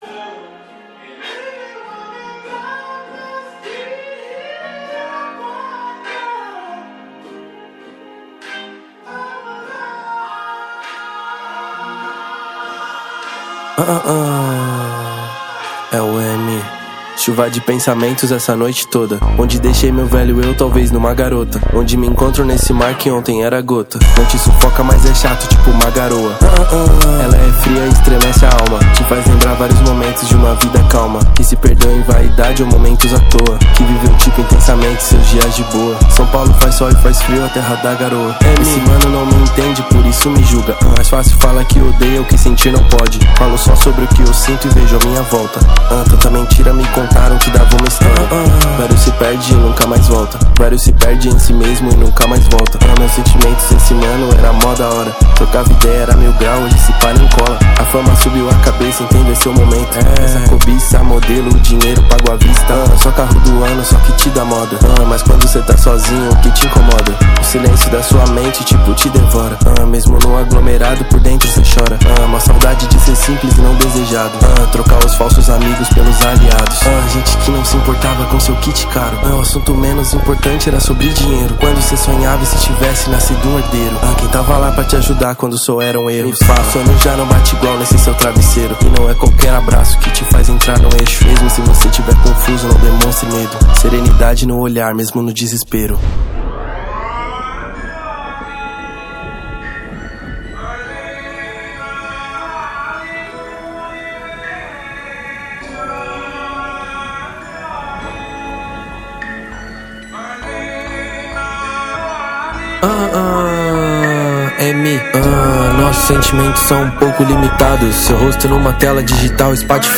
2024-09-25 22:30:41 Gênero: Trap Views